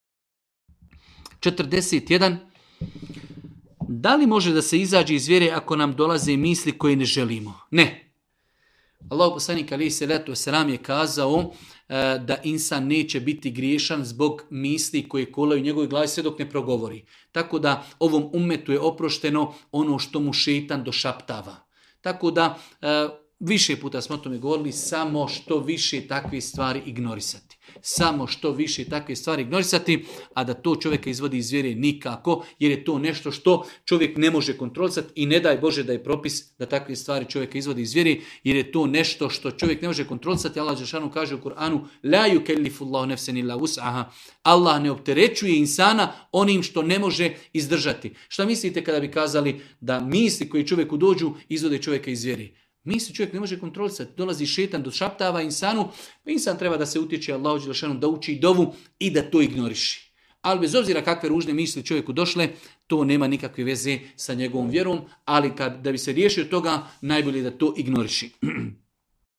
u video predavanju ispod